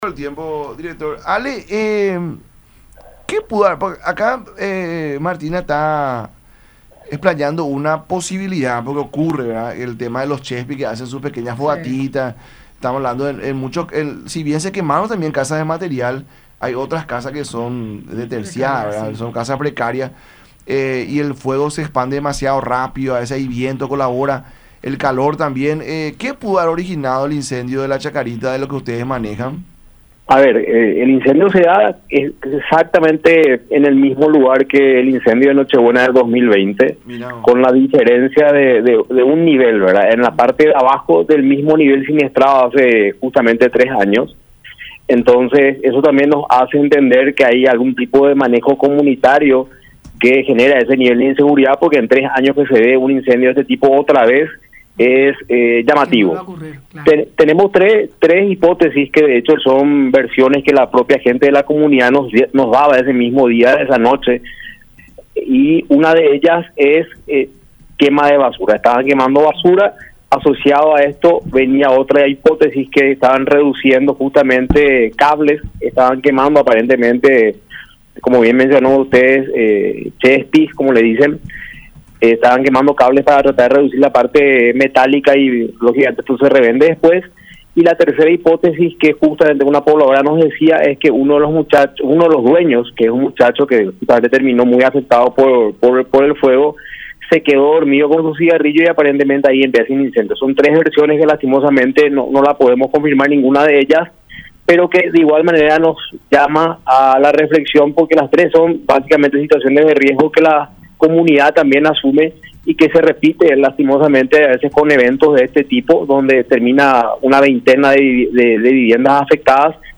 “Tenemos 3 hipótesis, quema de basura, quema de cables por parte de ‘chespis’ y la tercera es que uno de los dueños se quedó dormido con su cigarrillo, lastimosamente no podemos confirmar ninguna de igual manera nos llama a la reflexión”, sentenció en el programa “La Unión Hace La Fuerza” por radio La Unión y Unión Tv.